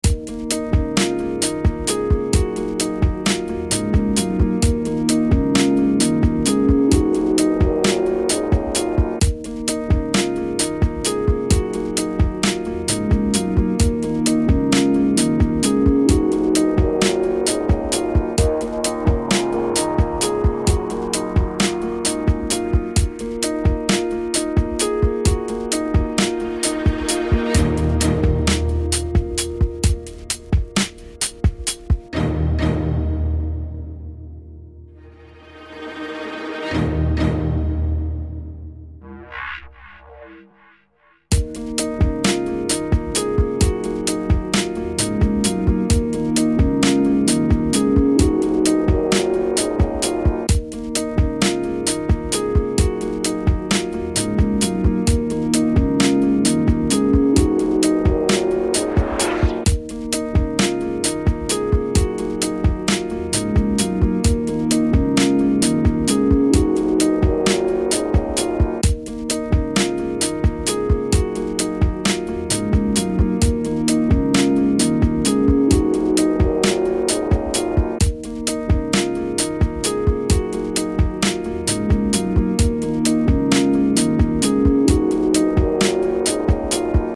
Tags: fantasy